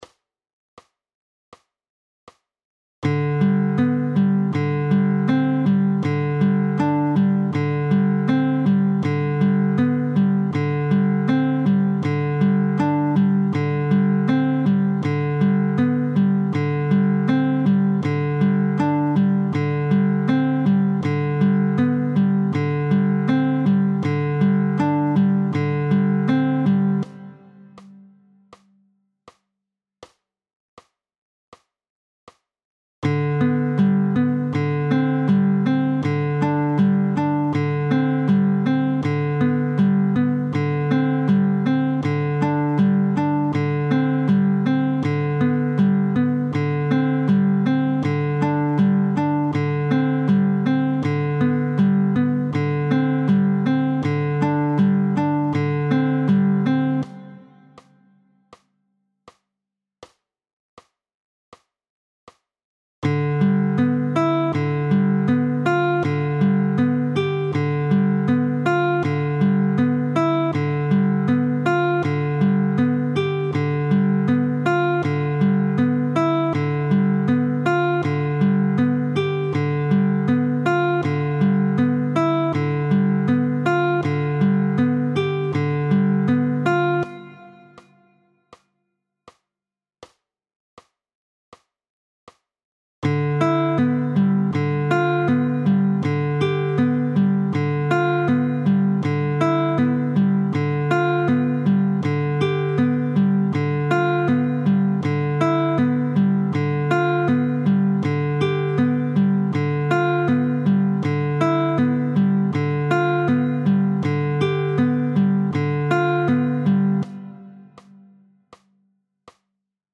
IV.2.1) I. Greifübungen 4. Finger, Bässe – Leersaiten: PDF
I.-Greifuebungen-4.-Finger-freier-AnschlagMetronomneu.mp3